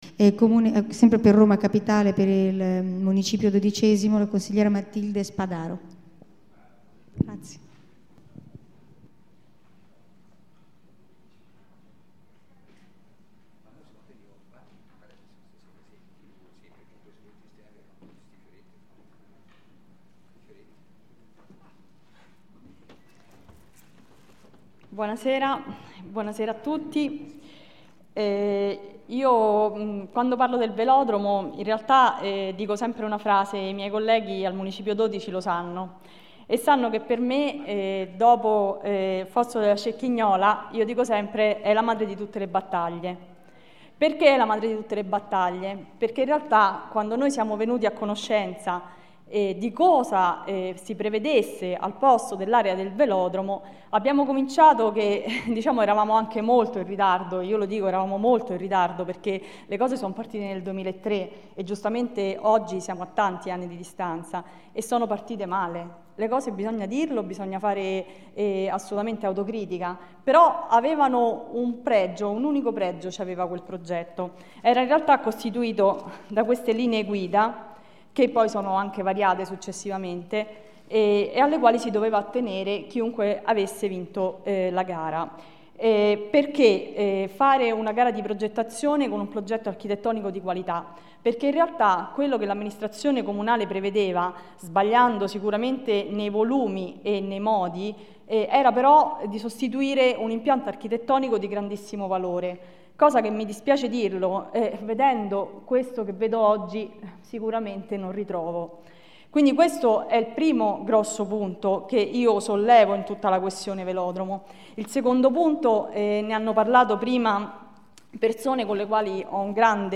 Assemblea partecipativa Ex VelodromoRegistrazione integrale dell'incontro svoltosi il 21 luglio 2011 presso l'Auditorium dell'Istituto "Massimiliano Massimo".
Matilde Spadaro Matilde Spadaro, consigliere Municipio Roma XII